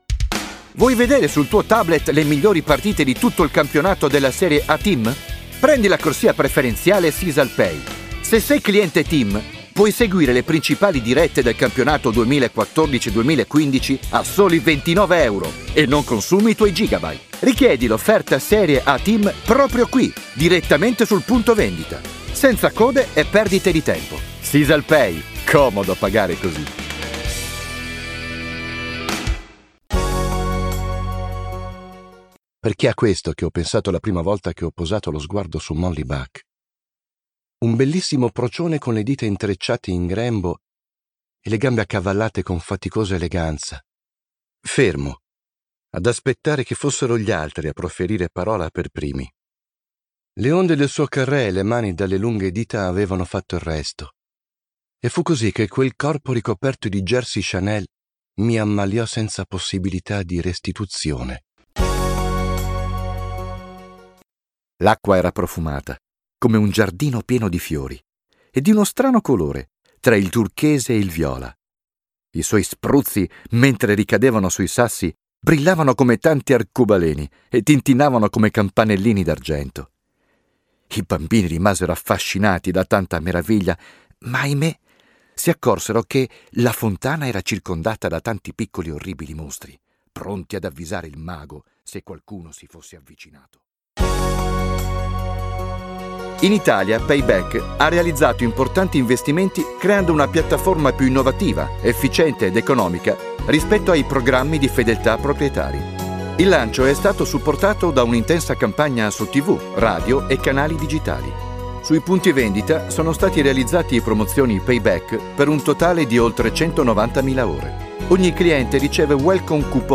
demo vocale